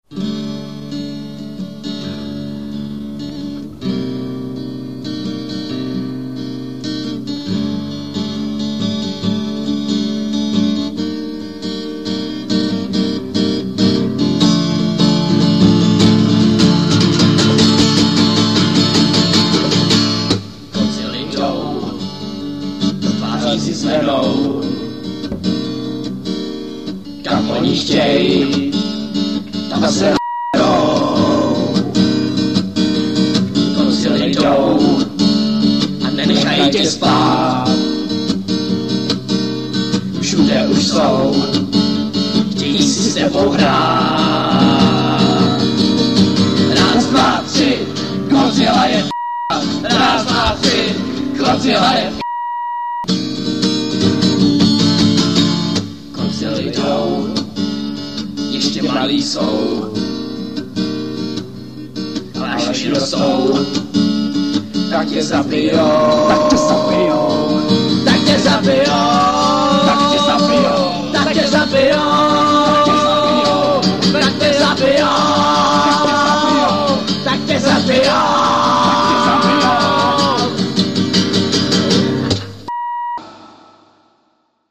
Nějakou tu skladbu už jsme složili, ale živou nahrávku jsme pořídili zatím jenom u jedné.
akordy na kytaru: ani nebudeme uvádět, je to strašně těžký